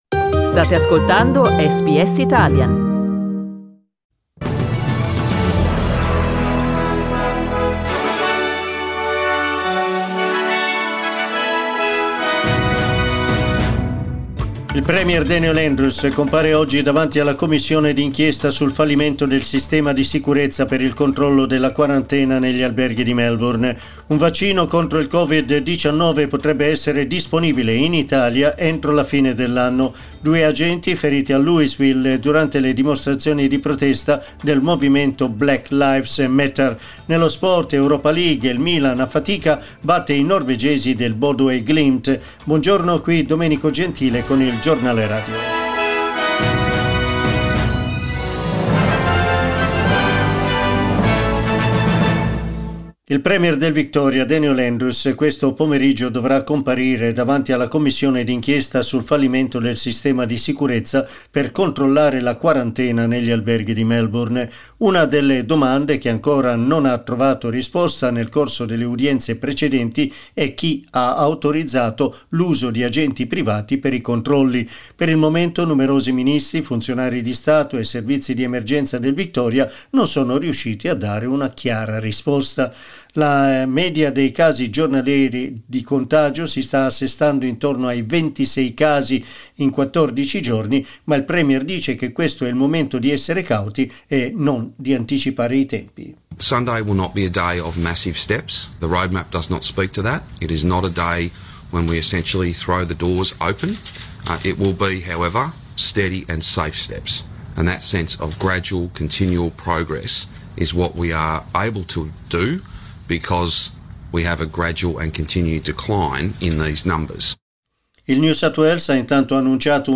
Our news bulletin (in Italian)